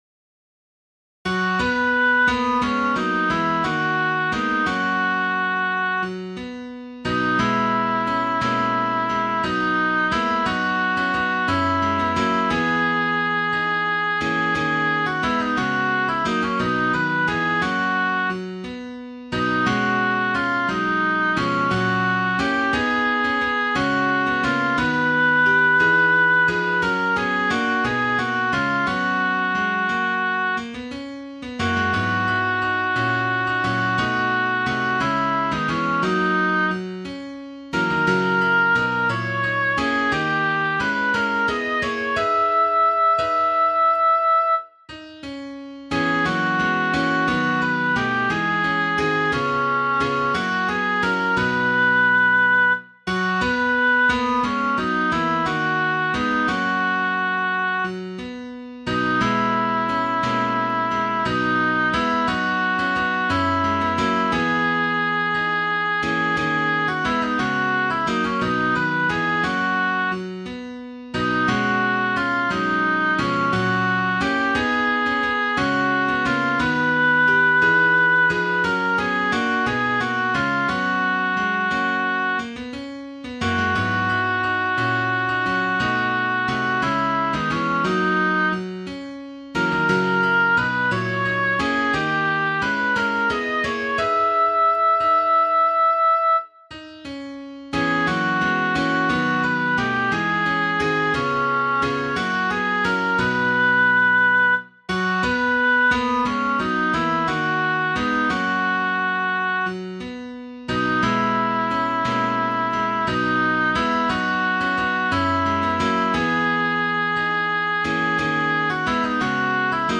i himmelen-sopran.mp3